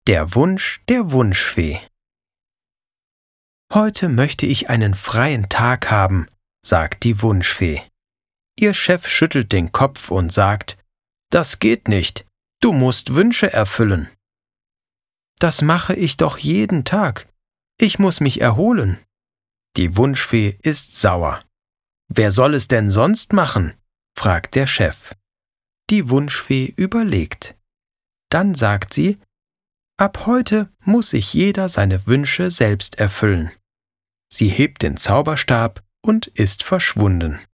Alle Texte wurden professionell eingesprochen.
81962-hoerprobe-ritter-elfen-feen.mp3